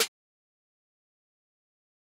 Snare (Alright).wav